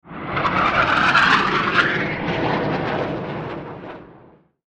Fighter Jet Flyby Sound Effect
Experience a realistic fighter jet flyby sound effect with a powerful military jet engine roar and intense high-speed flyover audio. This high-impact cinematic jet sound effect delivers authentic supersonic aircraft noise perfect for film production, action trailers, video games, and professional media projects.
Fighter-jet-flyby-sound-effect.mp3